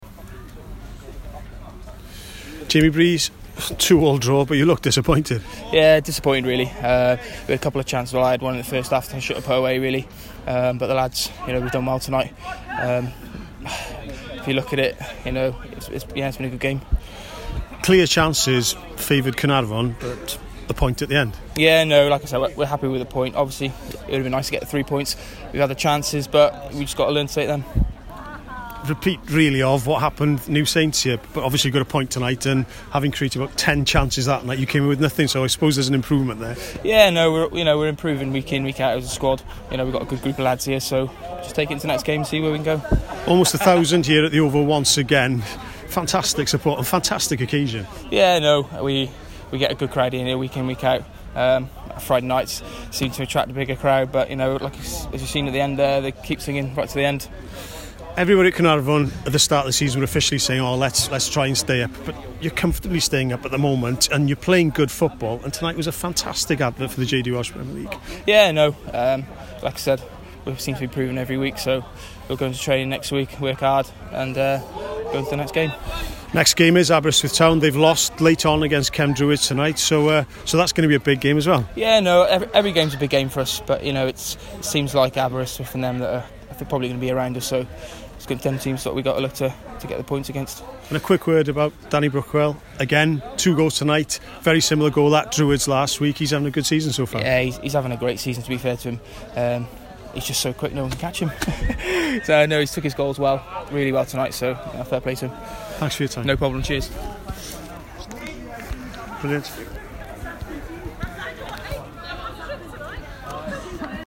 Cofi Army man speaks after 2-2 draw with Bala.